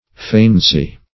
Search Result for " faineancy" : The Collaborative International Dictionary of English v.0.48: Faineance \Fai"ne*ance\, Faineancy \Fai"ne*an*cy\, n. [Cf. OF. faineance.